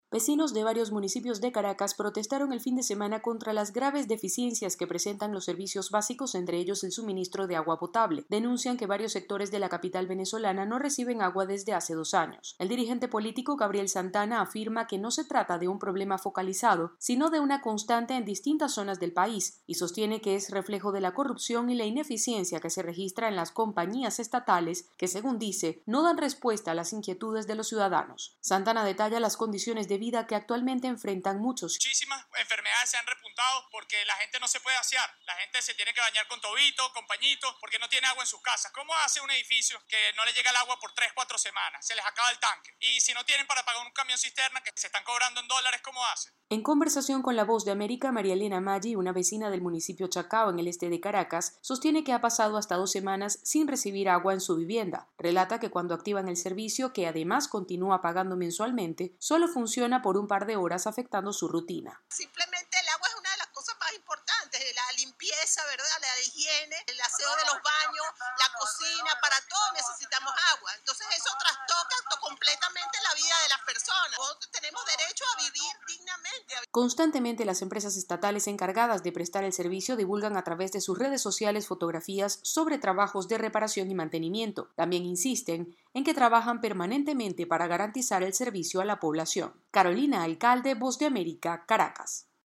VOA: Informe desde Venezuela